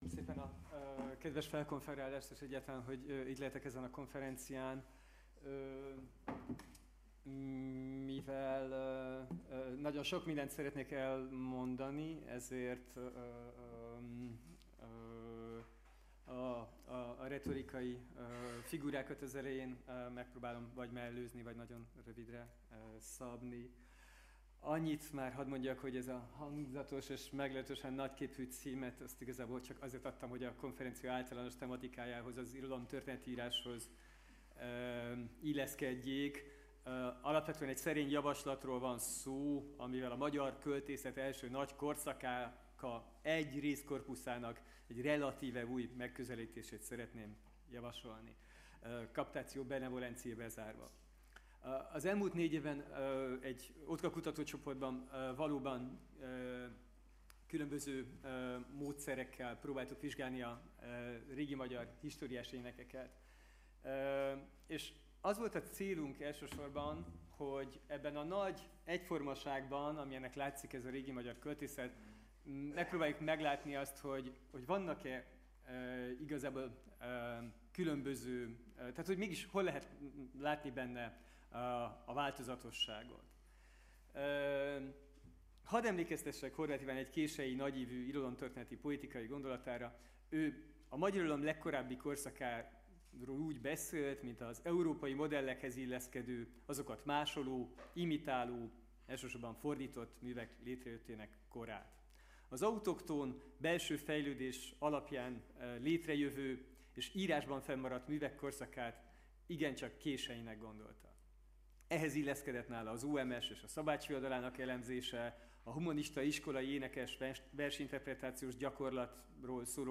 lecturer